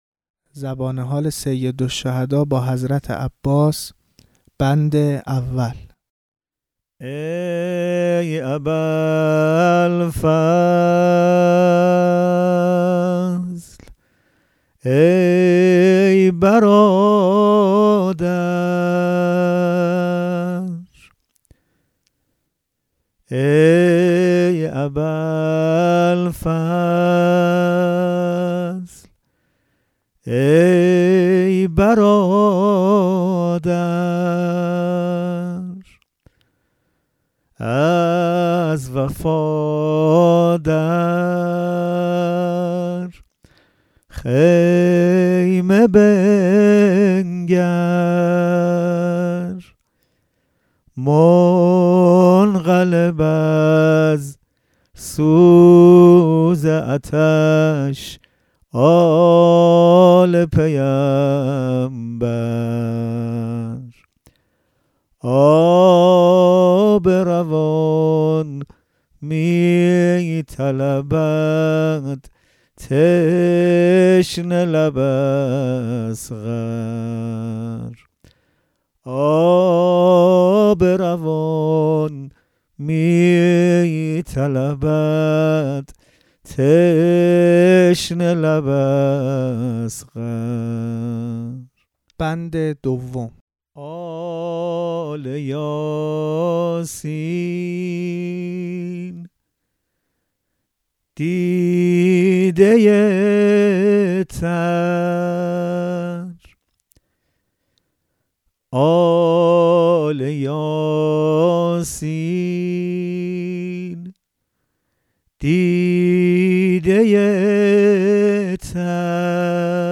ذکر سقائی ( سبک شماره 11) مصیبت عطش طفلان با حضرت عباس